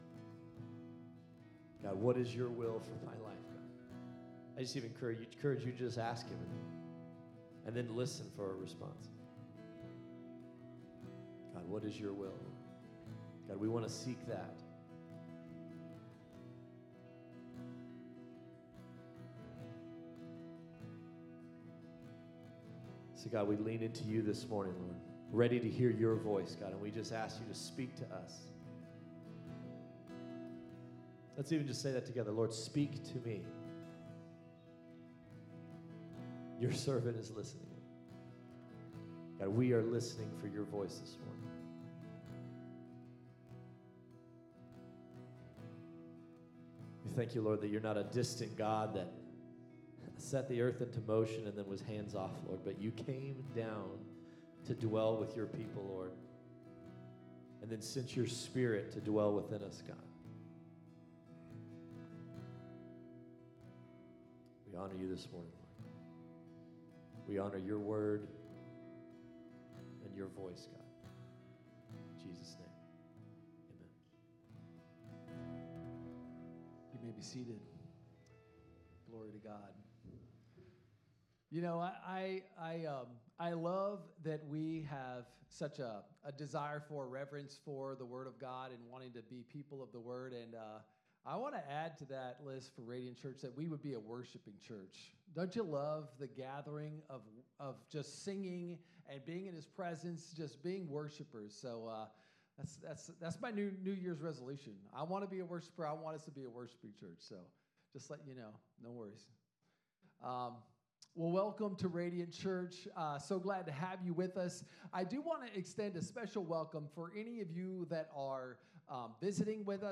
Sermons | Radiant Church